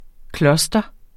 cluster substantiv, intetkøn Bøjning -et eller clustret, clustre eller -s, clustrene Udtale [ ˈklʌsdʌ ] Oprindelse fra engelsk cluster 'klynge, klase' Betydninger 1.